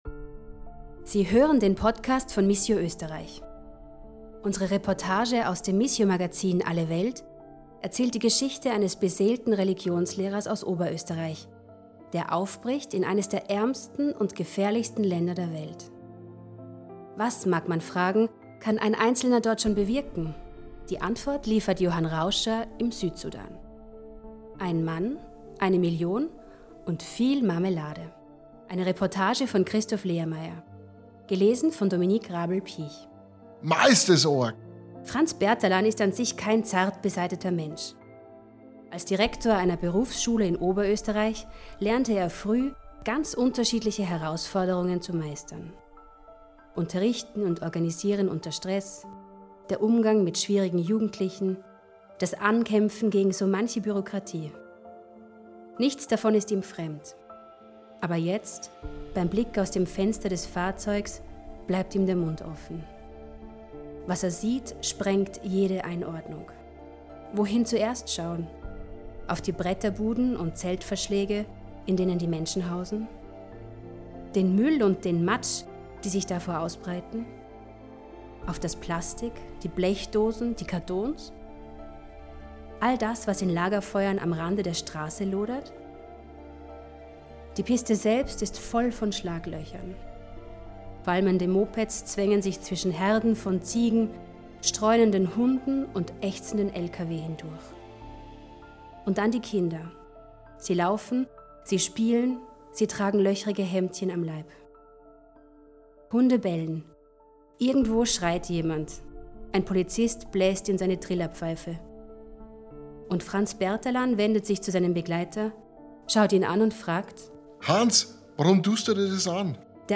Reportage zum Anhören: Ein Mann, eine Million und viel Marmelade